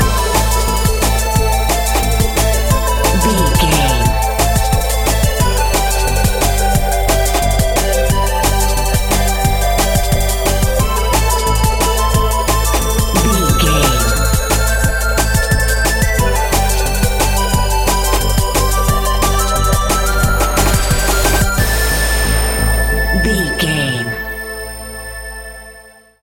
Aeolian/Minor
Fast
aggressive
dark
driving
energetic
groovy
drum machine
synthesiser
electronic
sub bass
synth leads
synth bass